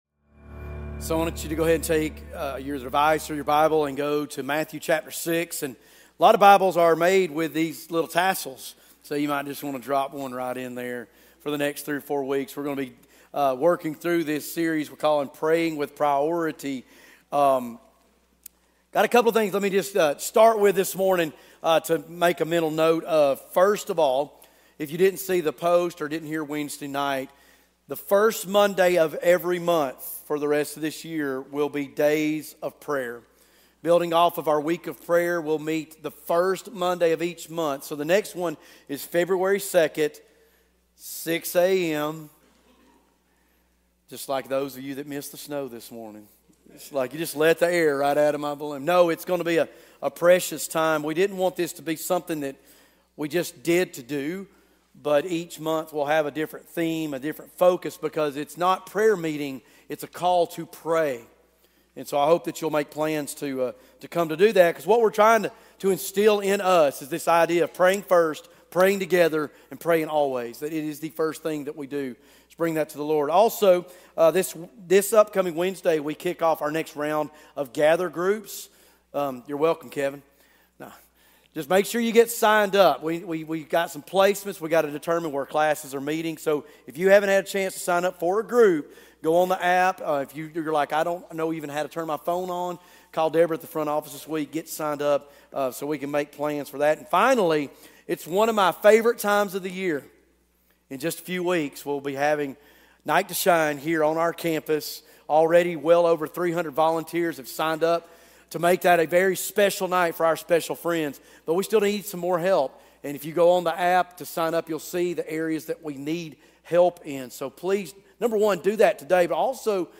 Sunday message.